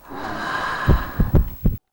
drain.mp3